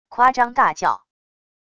夸张大叫wav音频